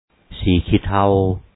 sǐi-khíi thao gray